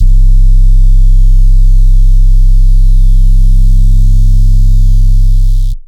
808 - BUG ZAPPER.wav